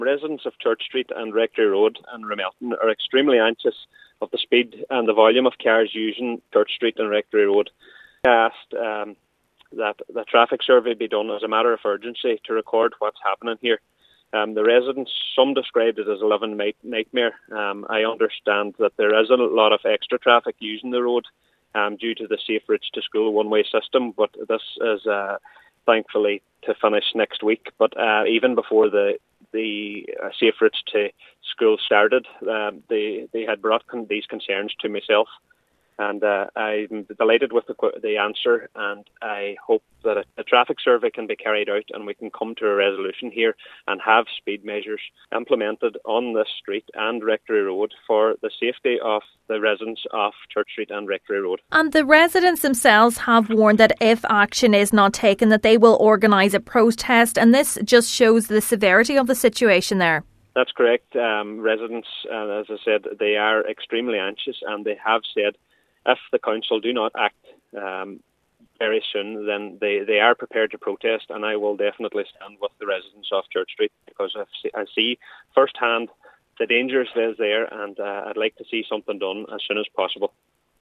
Councillor Pauric McGarvey says it’s vital traffic calming measures are installed: